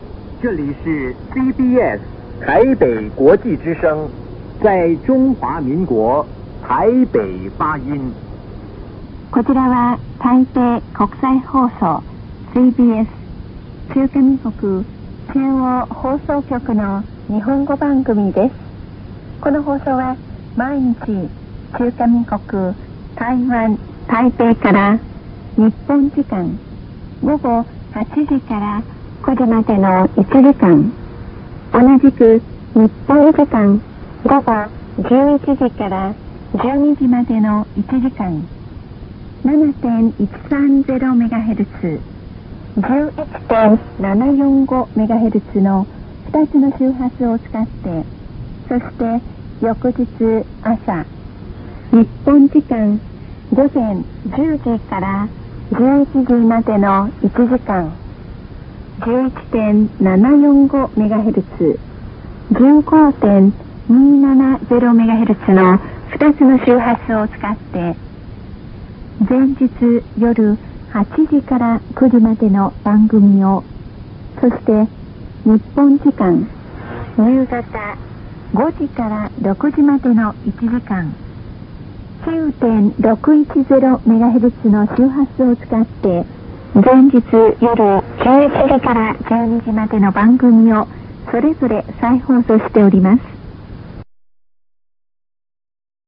放送開始部分の録音が聴けます